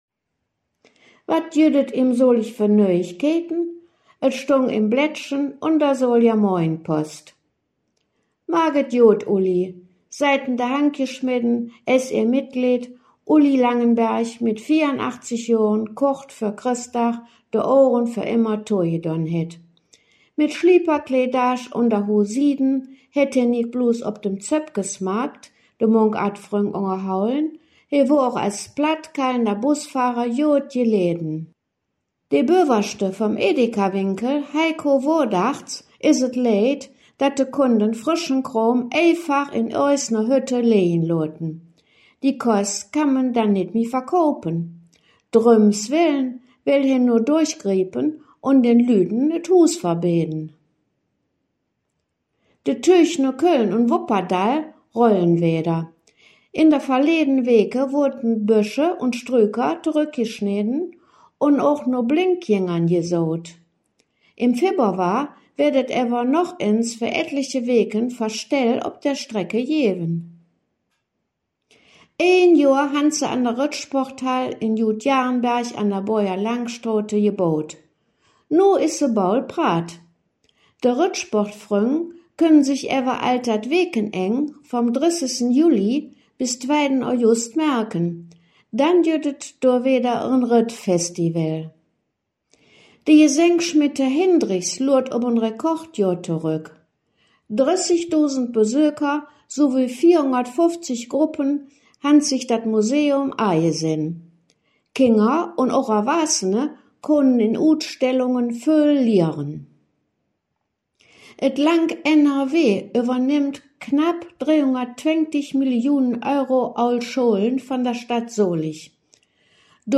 Solinger Platt Nachrichten (26/02)
Nöüegkeïten op Soliger Platt